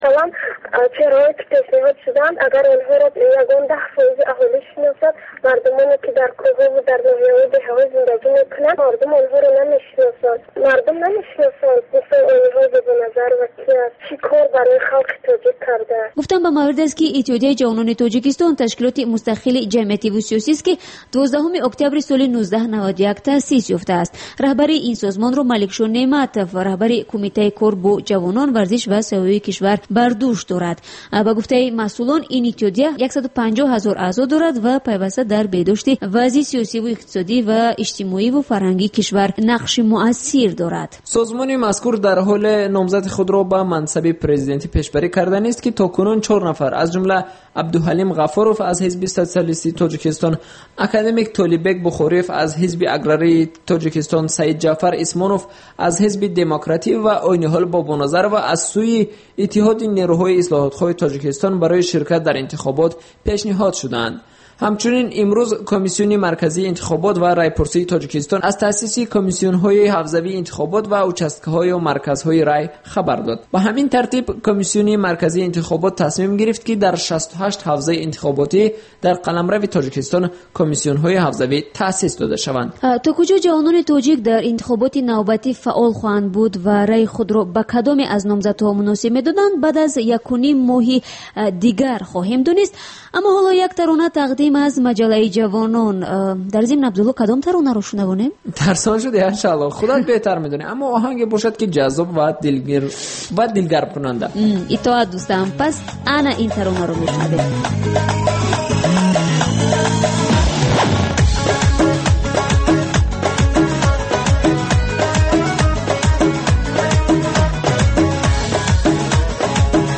Дин ва ҷомеа. Гузориш, мусоҳиба, сӯҳбатҳои мизи гирд дар бораи муносибати давлат ва дин. Шарҳи фатво ва нукоти мазҳабӣ.